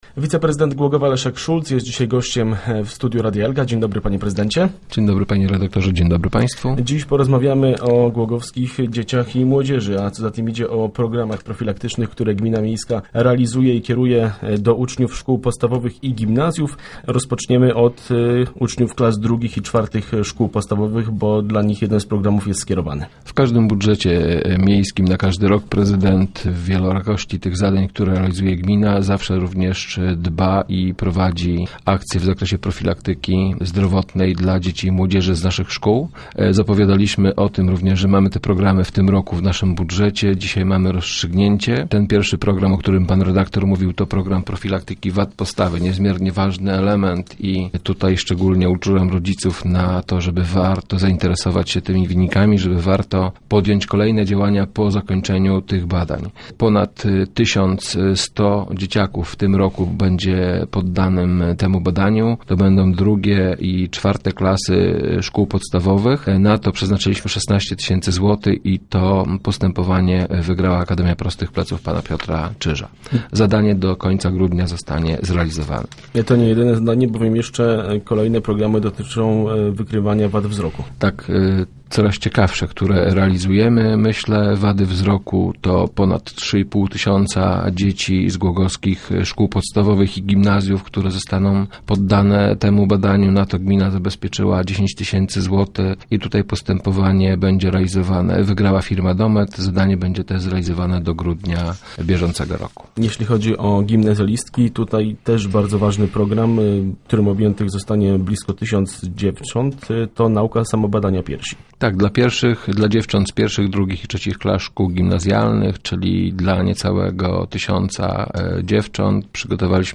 Adresowane są do uczniów podstawówek i gimnazjów. Na ten temat rozmawialiśmy w radiowym studiu z wiceprezydentem Głogowa Leszkiem Szulcem.